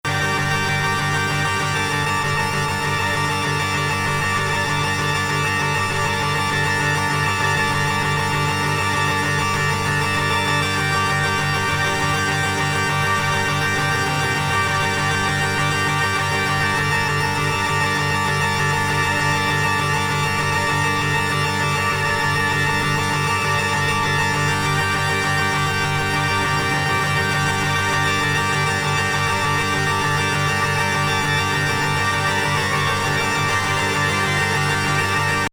パイプオルガンによる宗教歌のような重厚な響きの電気オルガンが鳴り響くインナートリッピーな傑作！！！